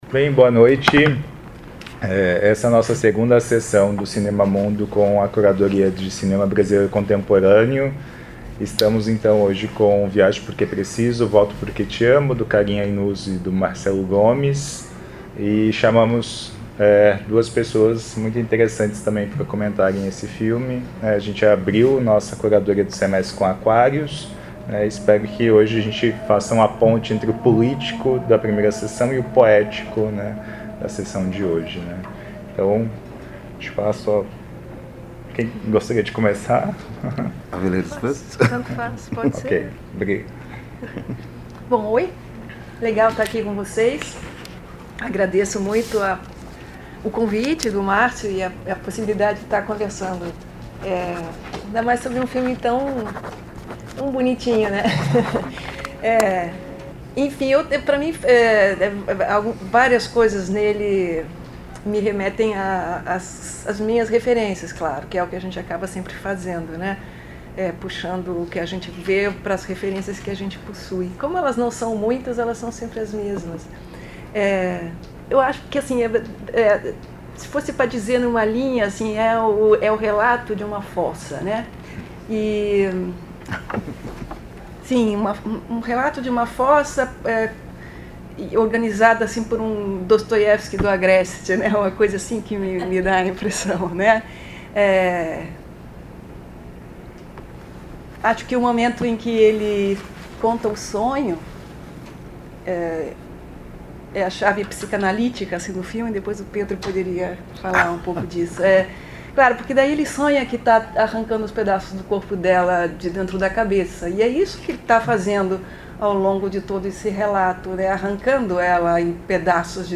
Comentários dos debatedore(a)s convidado(a)s